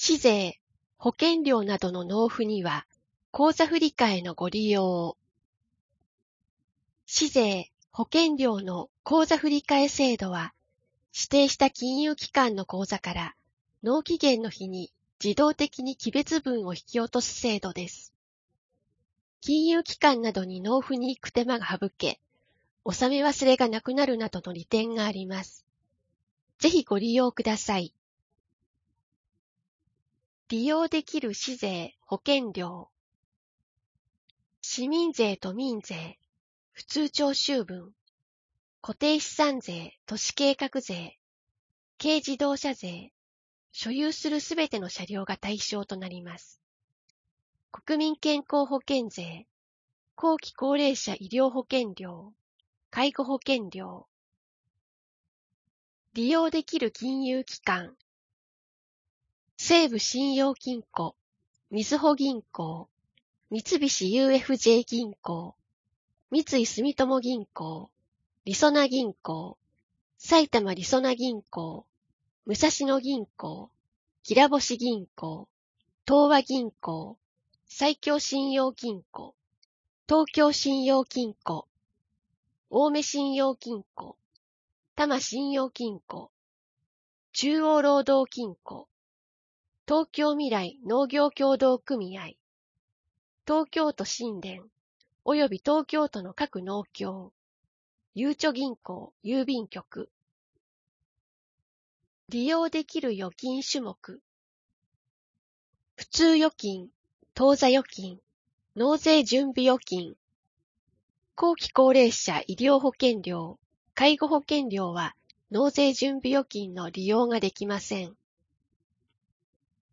声の広報（令和2年4月15日号）